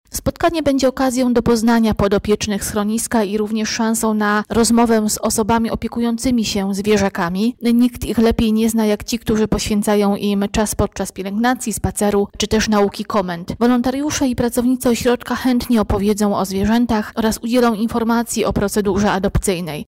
– mówi